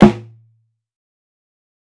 Bongo Snare 1.wav